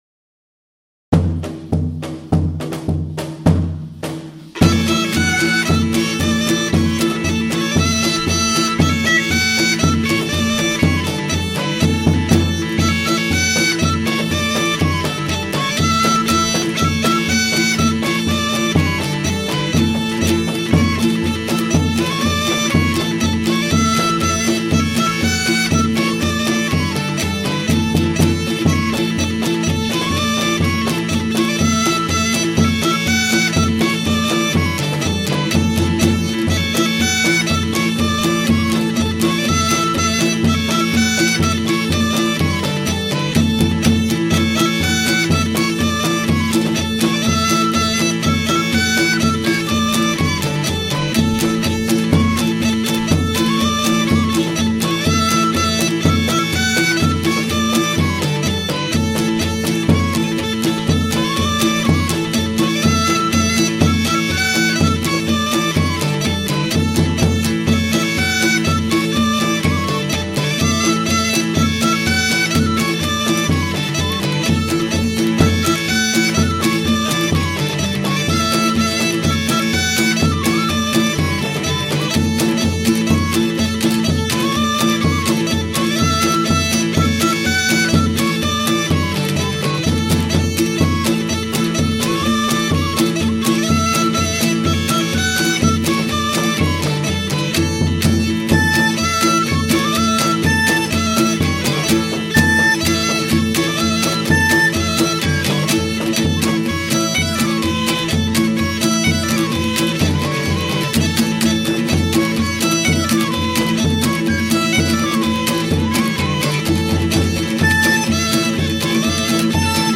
Az újrateremtett kor Balassi Bálint költészetéből is ismerős, felidézéséhez hallgassunk meg bevezetőül a Héttorony Hangászok előadásában felcsendülő hajdútáncokat…